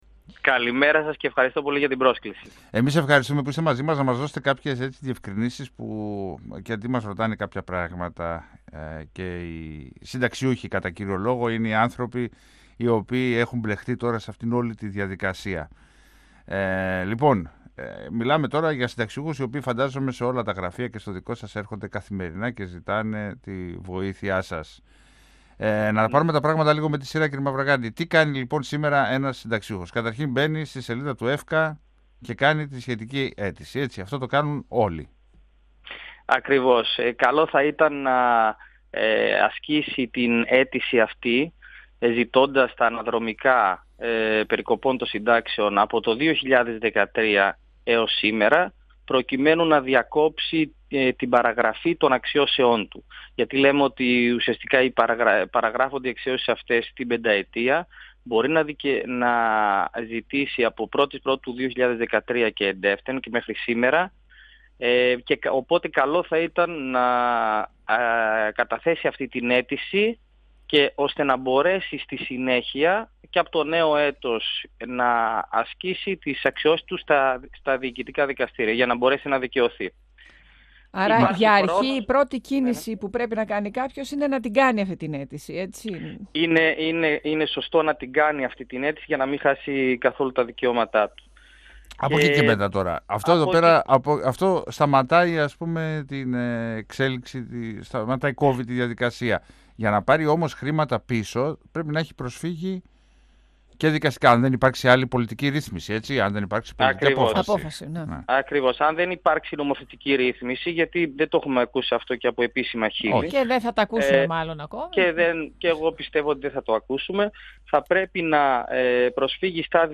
Συμπλήρωσε ότι οικονομικά συμφέρει τους συνταξιούχους να προχωρήσουν σε ομαδικές προσφυγές 102FM Συνεντεύξεις ΕΡΤ3